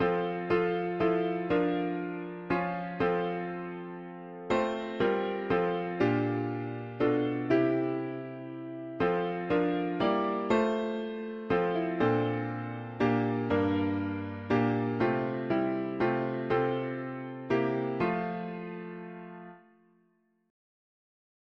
u… english theist 4part
Key: F major